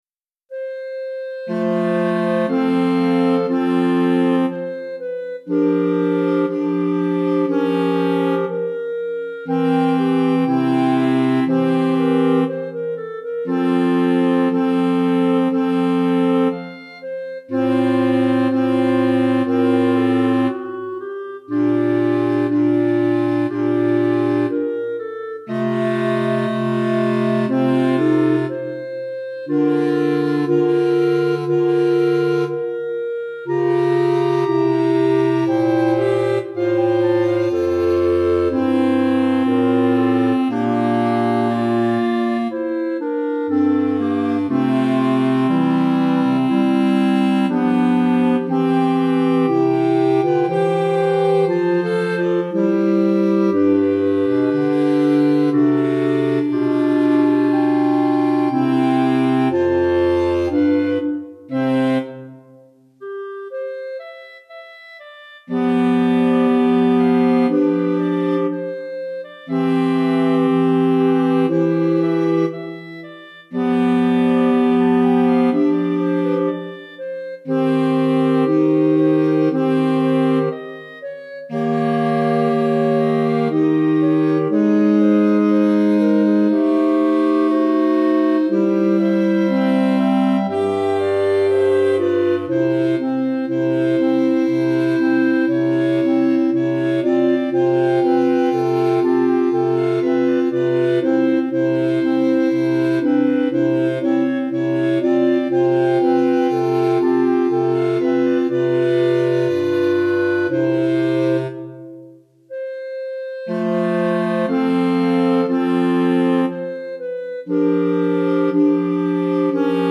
4 Clarinettes